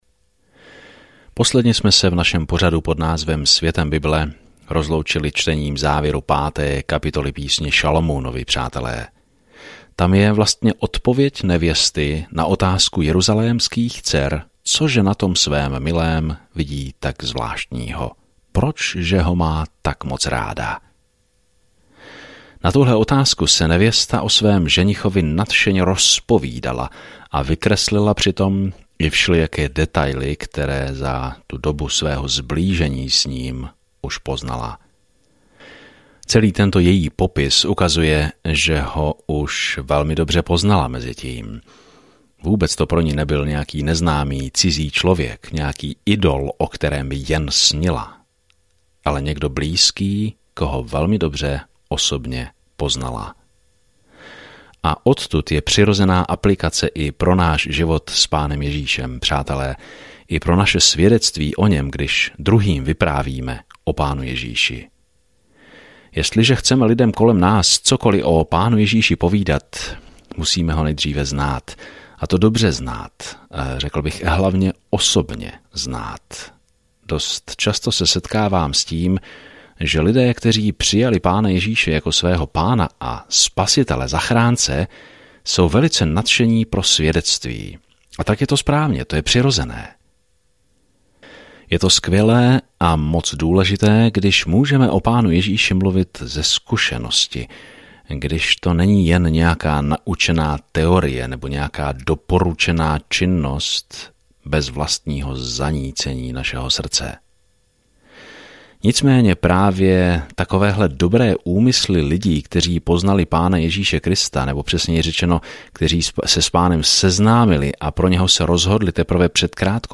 Písmo Píseň 6 Den 9 Začít tento plán Den 11 O tomto plánu Song of Solomon je malá milostná píseň oslavující lásku, touhu a manželství v širokém srovnání s tím, jak nás Bůh poprvé miloval. Denně procházejte Song of Solomon, zatímco budete poslouchat audiostudii a číst vybrané verše z Božího slova.